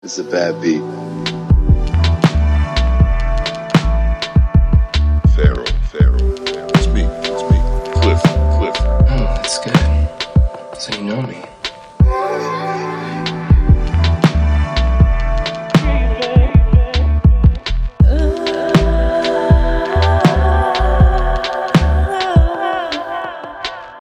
New Beat